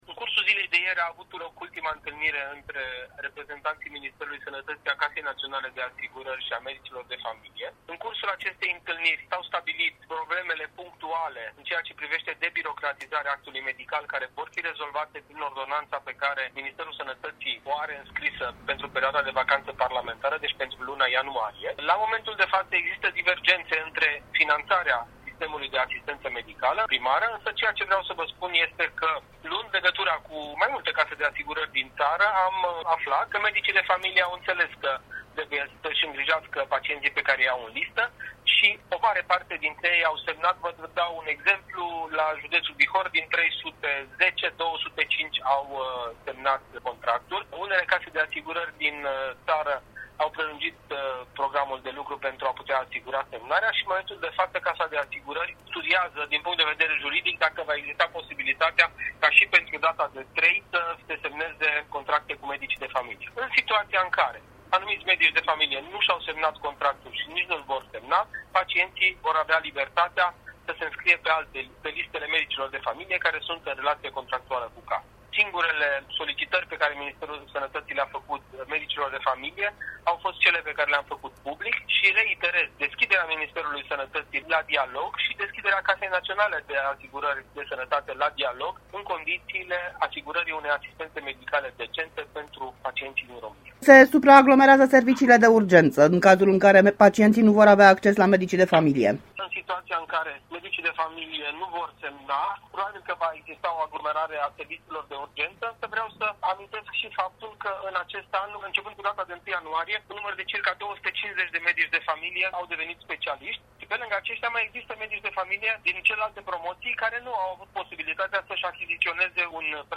Intrebat despre problemele cu care se pot confrunta la nivel national pacientii care nu isi permit plata retetelor compensate de la 3 ianuarie, ministrul sanatatii Florian Bodog a declarat in exclusivitate pentru Bucuresti FM: